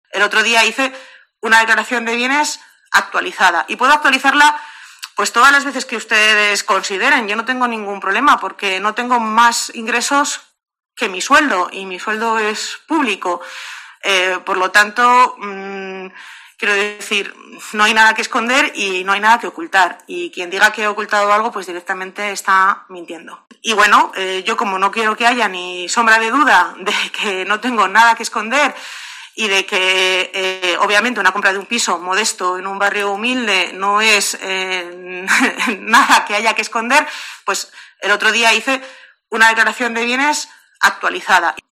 En rueda de prensa sobre otro asunto, y preguntada acerca de por qué ha tardado nueve meses en dar a conocer la compra de su vivienda, ha dicho: "Se ha dicho que yo he ocultado la compra de una vivienda, cosa que es absolutamente falsa".